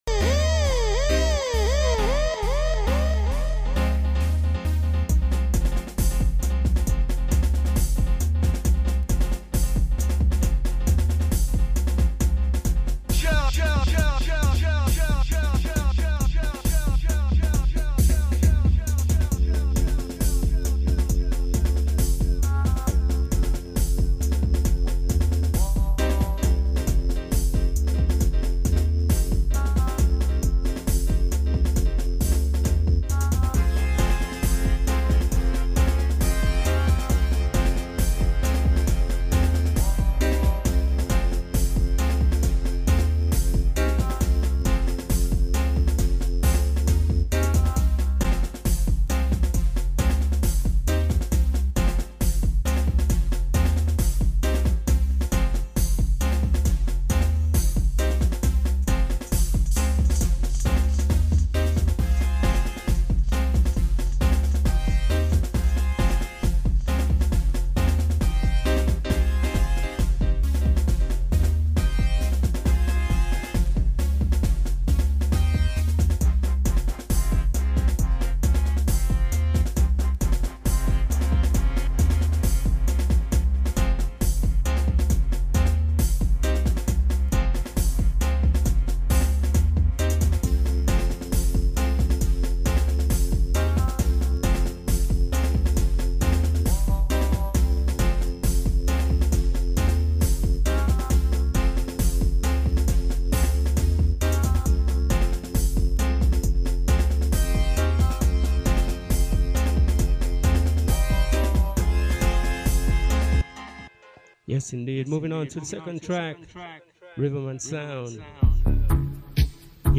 Live & Direct.